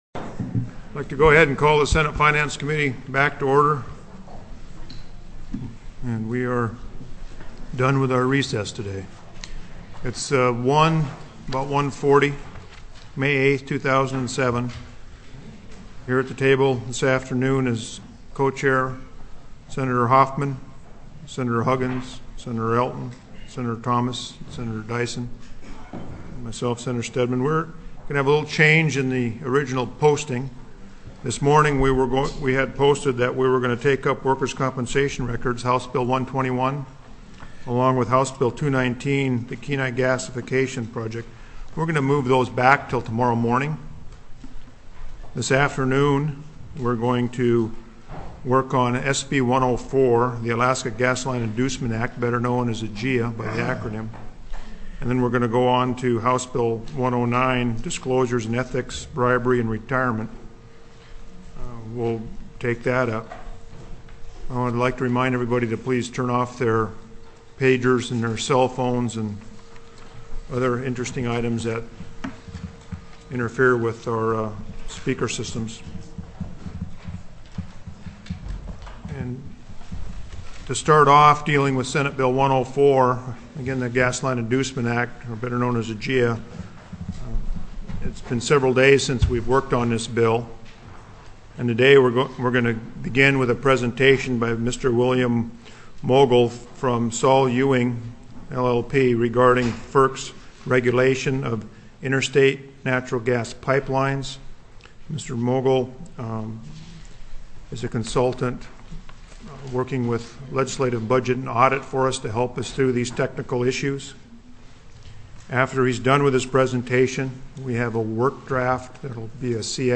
+ teleconferenced
+= SB 104 NATURAL GAS PIPELINE PROJECT TELECONFERENCED